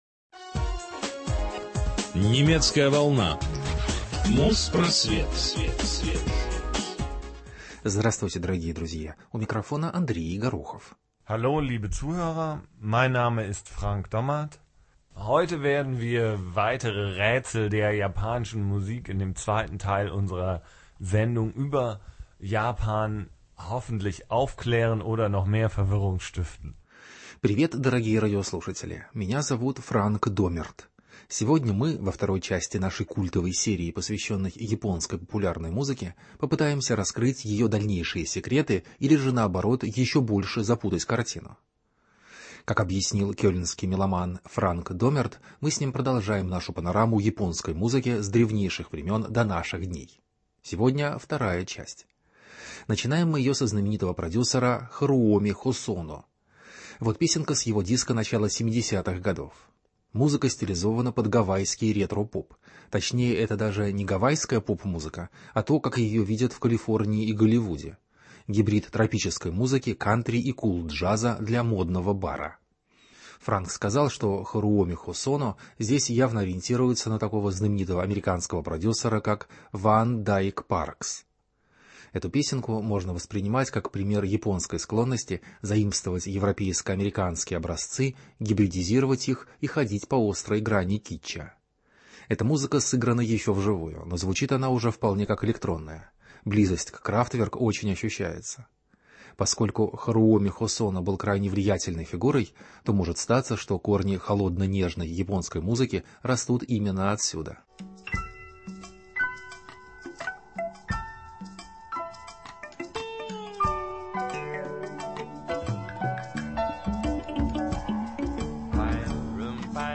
Панорама японской поп-музыки